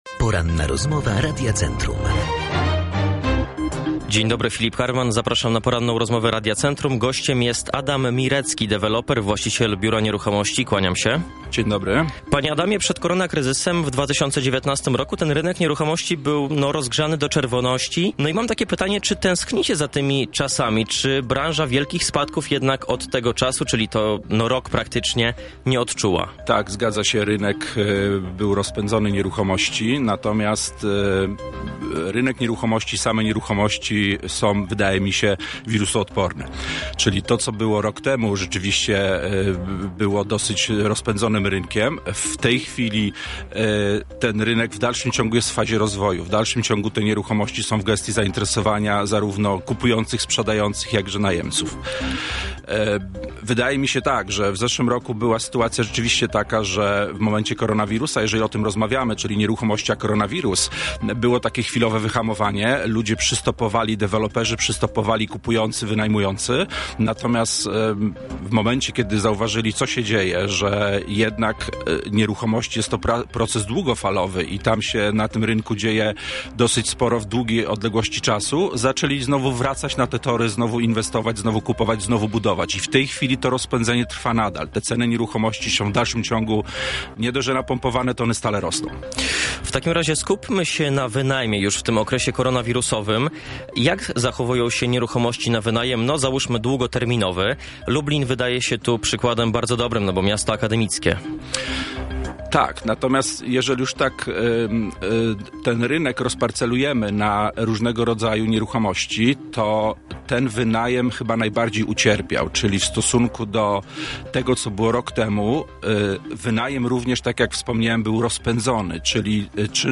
Całą rozmowę